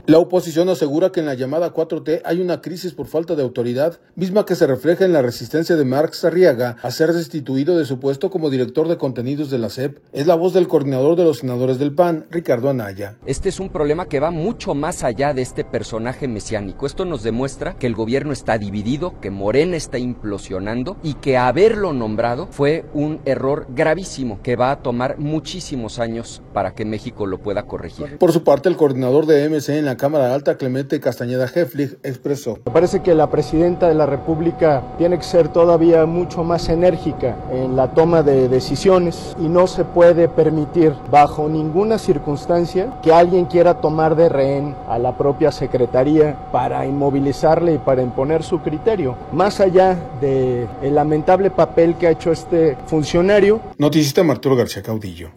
La Oposición asegura que en la llamada 4T hay una crisis por falta de autoridad, misma que se refleja en la resistencia de Marx Arriaga a ser destituido de su puesto como director de contenidos de la SEP. Es la voz del coordinador de los senadores del PAN, Ricardo Anaya.